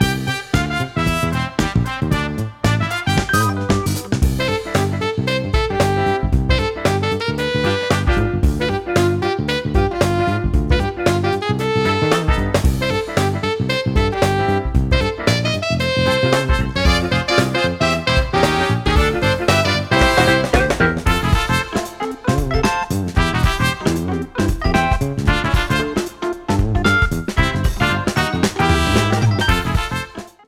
Updated to high quality & fade out